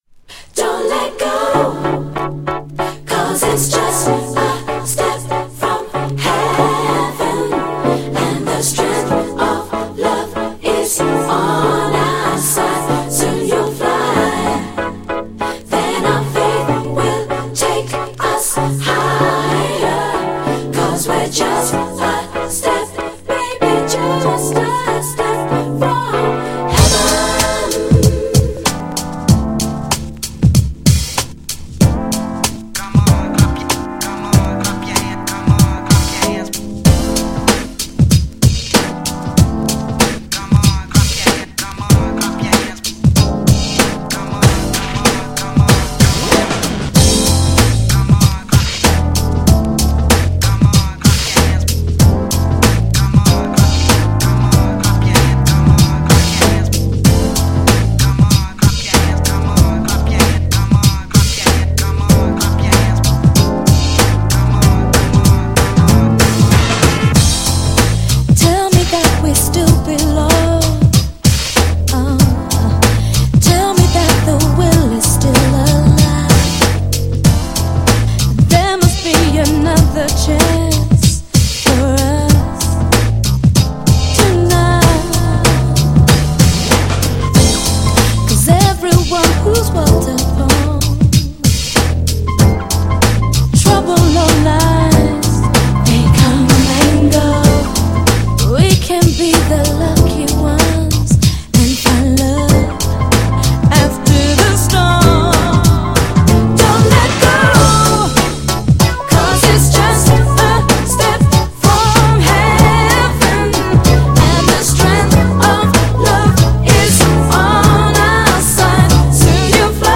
90年代のUK R&Bを代表するグループ!!
GENRE R&B
BPM 96〜100BPM